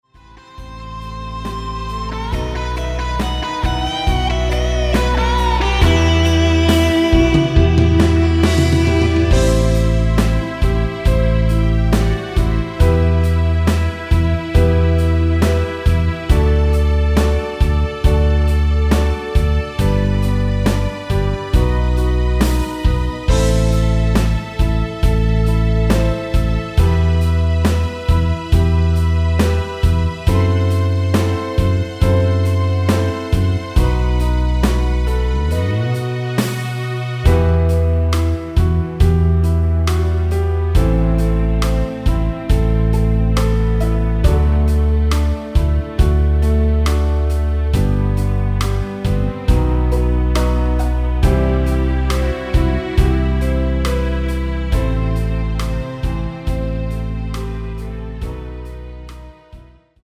원키에서(+2)올린 MR입니다.
Em
앞부분30초, 뒷부분30초씩 편집해서 올려 드리고 있습니다.